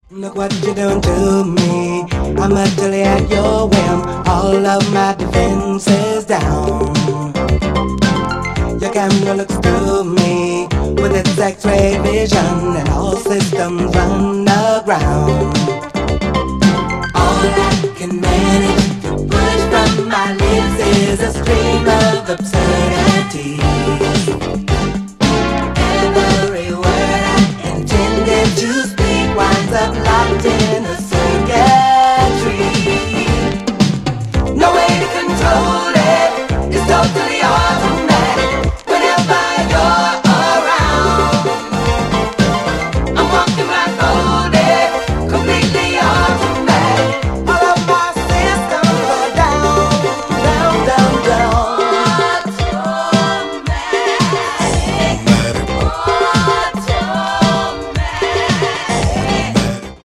Boogie. Disco. Electro